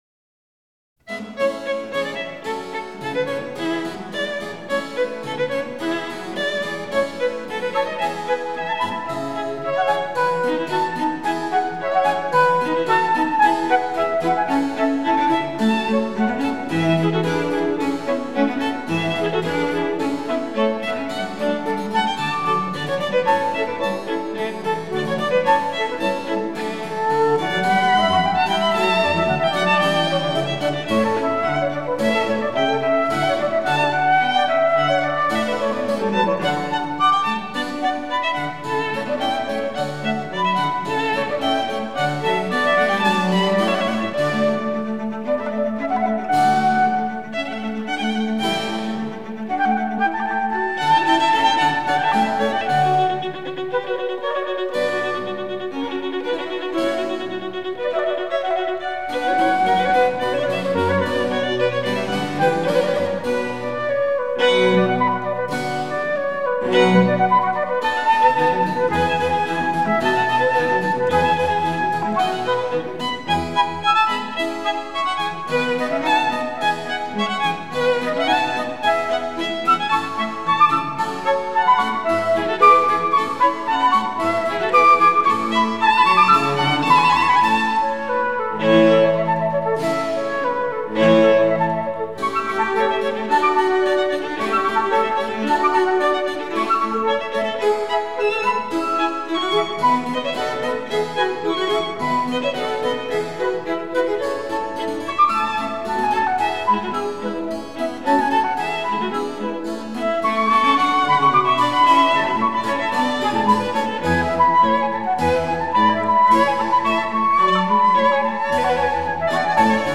各件乐器的线条呈现得特别美妙，现场感实足，从容不迫、气度不凡。高贵与典雅贯穿始终，确实是一款权威的演绎。